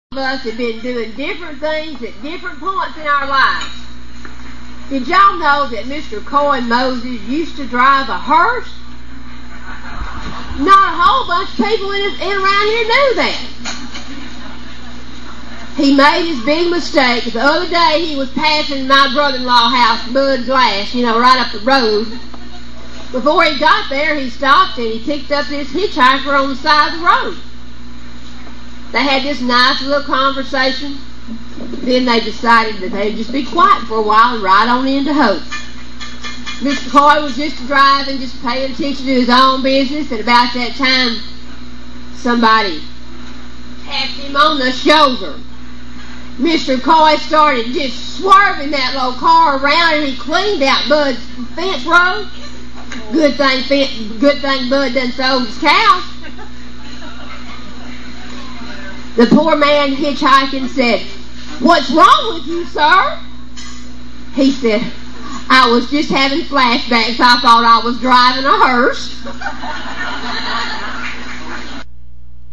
VFD Firehouse Jamboree Story!